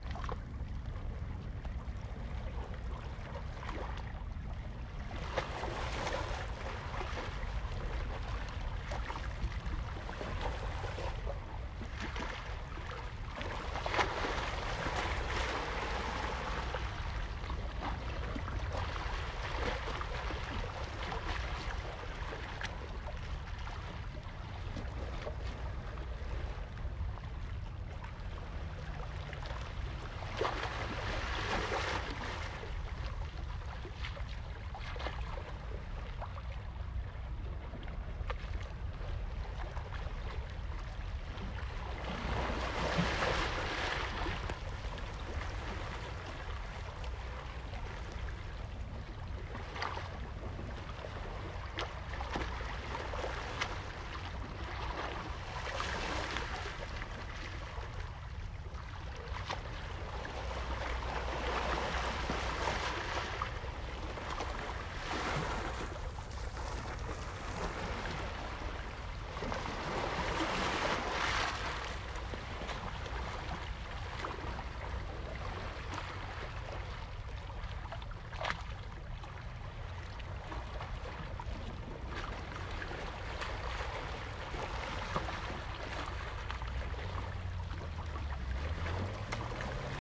【環境音】朝の海 / 海 ocean